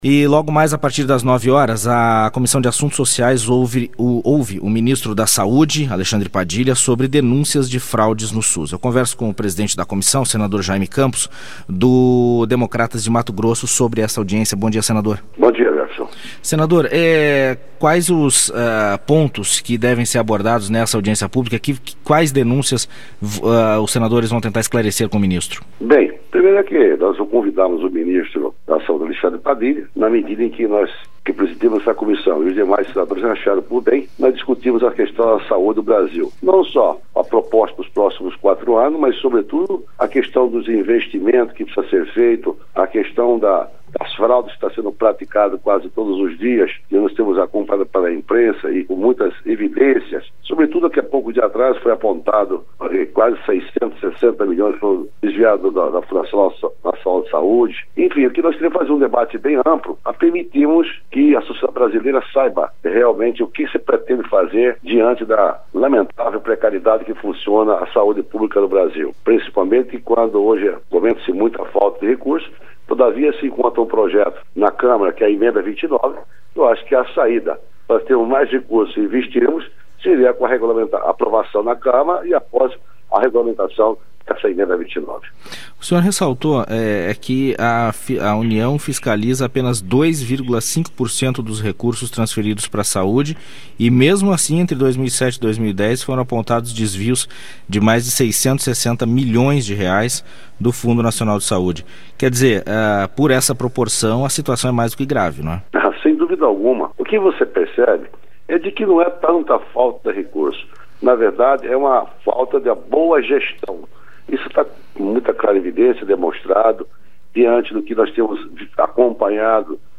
Entrevista com o senador Jayme Campos (DEM-MT), presidente da Comissão Assuntos Socias.